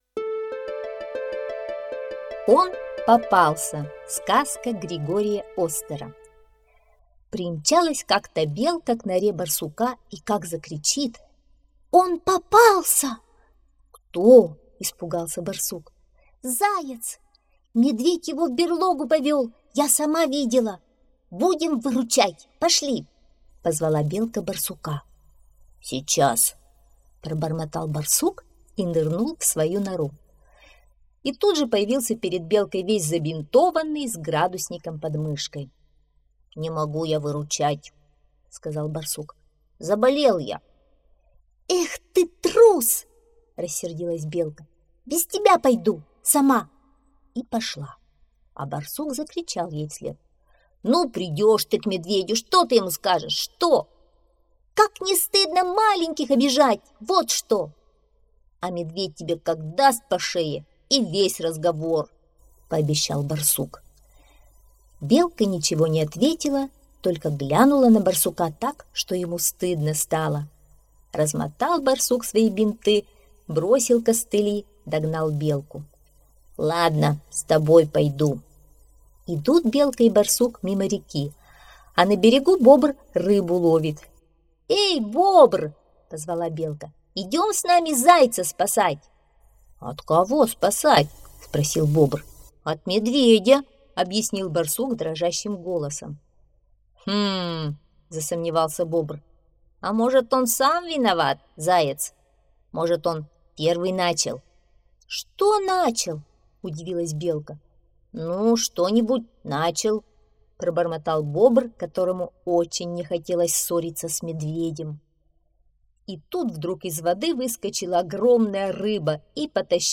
Он попался — аудиосказка Григория Остера.